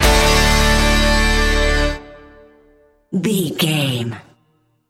Ionian/Major
electric guitar
drums
bass guitar
violin